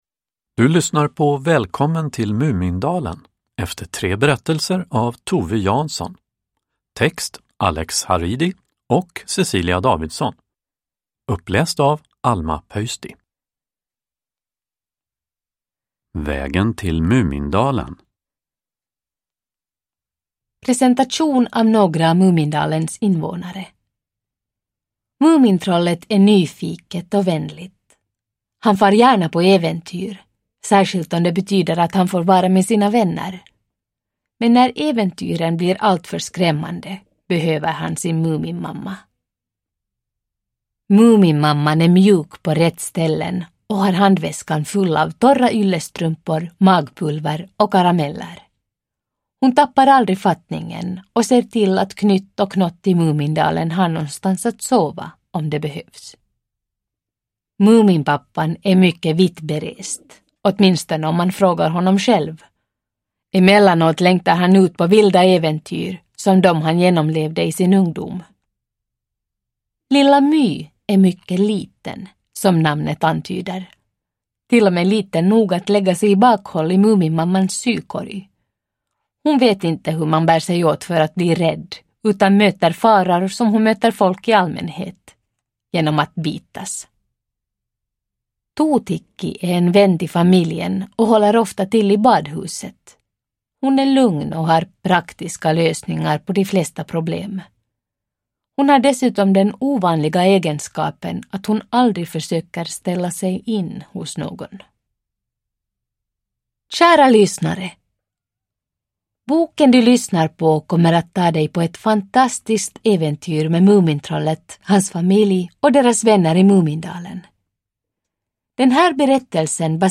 Uppläsare: Alma Pöysti
Ljudbok